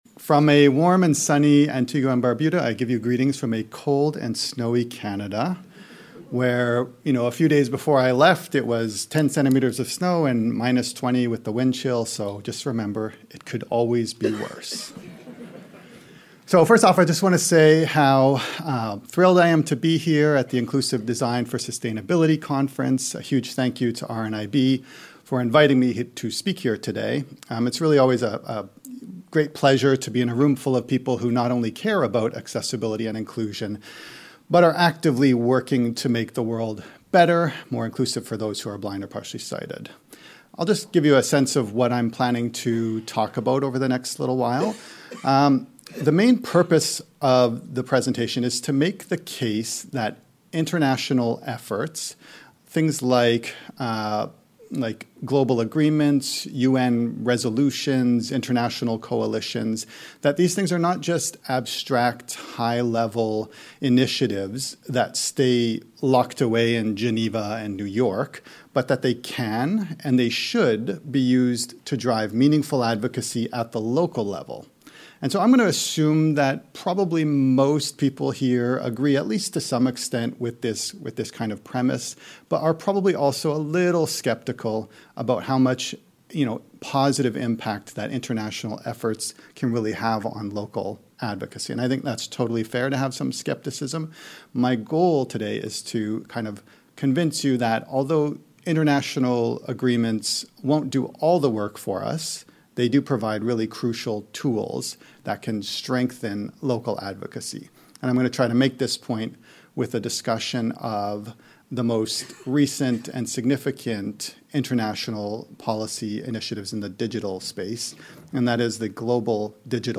Earlier this week, RNIB Scotland's Inclusive Design for Sustainability Conference returned to Glasgow for an exciting two-day event.